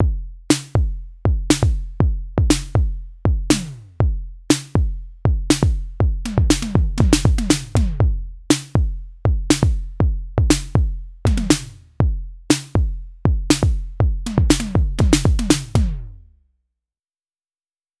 Once my toms were ready I panned them slightly apart to create some space and added them to my sequence.
The pattern with (the very sloppily recorded) toms included.